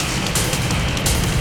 RI_DelayStack_170-01.wav